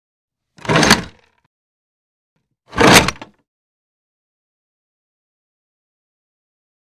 Very Heavy Wood Frame Window, Scrapes And Squeaks To Close, X2